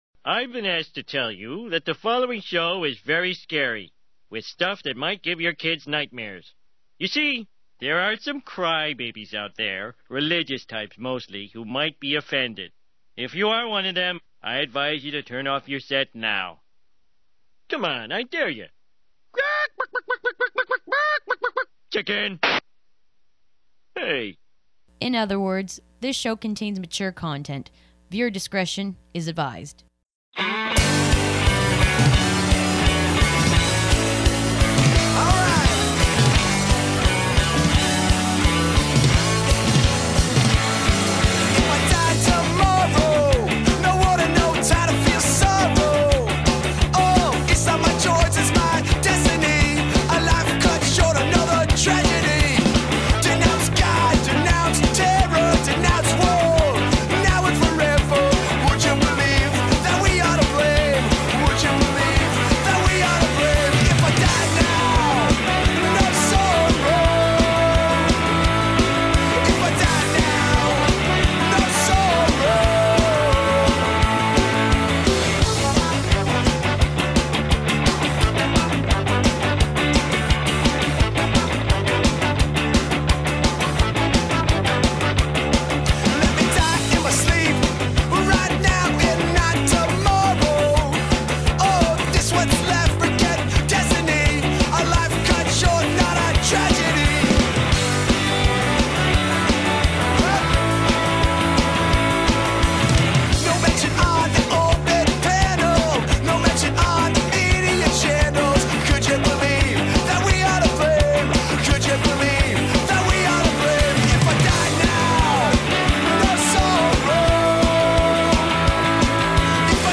Hour Long Punk Music Program